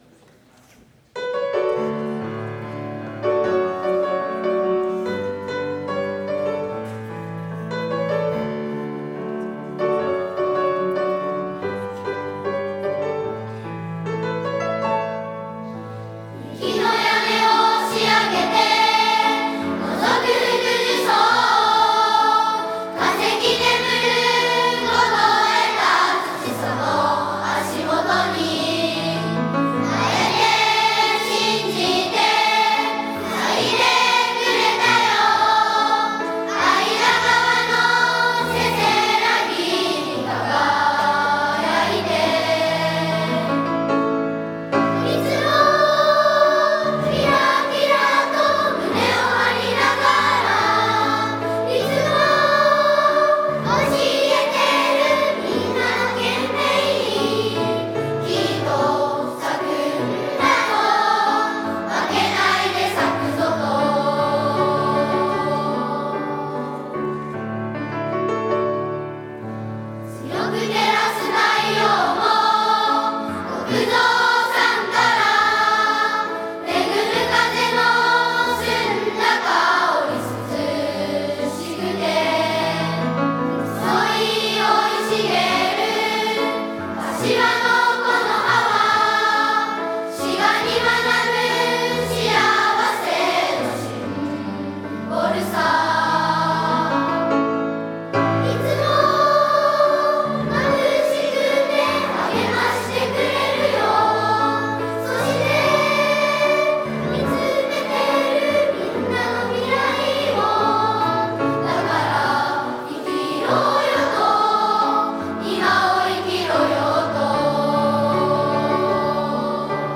児童歌唱